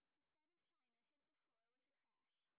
sp20_street_snr20.wav